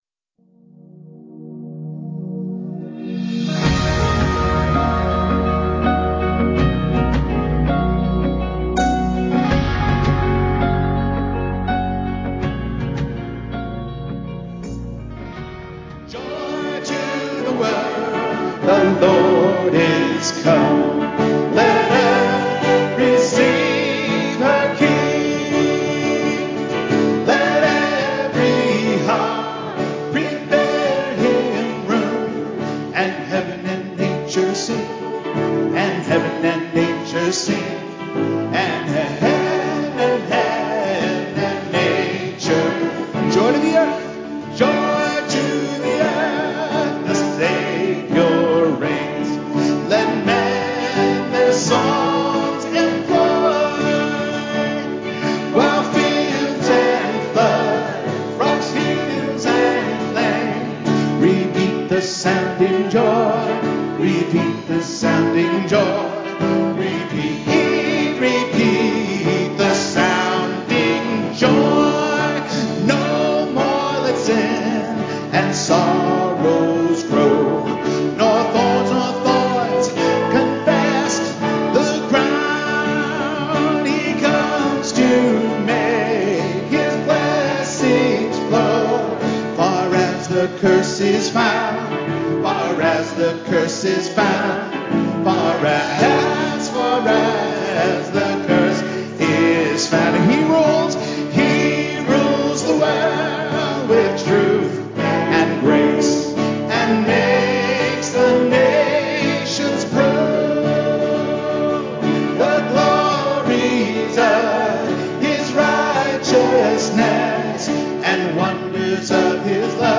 Sunday-Morning-Worship-December-5-2021-CD.mp3